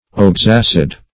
oxacid - definition of oxacid - synonyms, pronunciation, spelling from Free Dictionary Search Result for " oxacid" : The Collaborative International Dictionary of English v.0.48: Oxacid \Ox`ac"id\ ([o^]ks`[a^]s"[i^]d), n. (Chem.)
oxacid.mp3